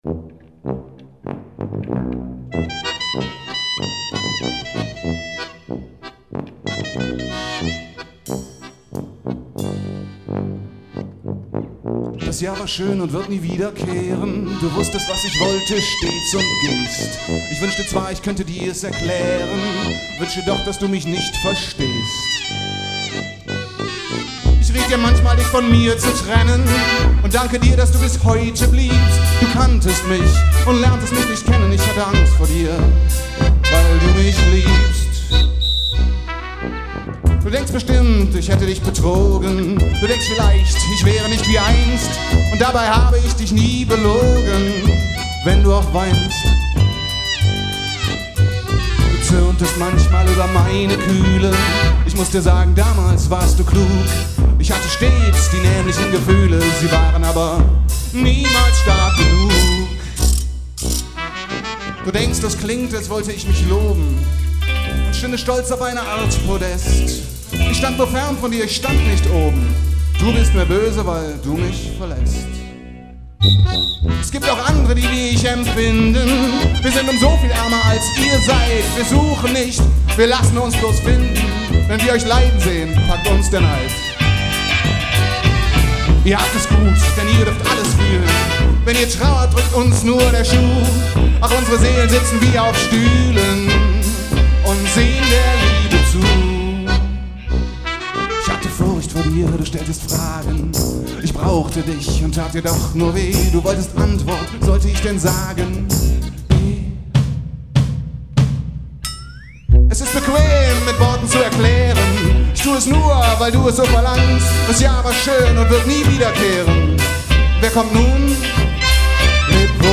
Konzertmitschnitte
Violine
Violoncello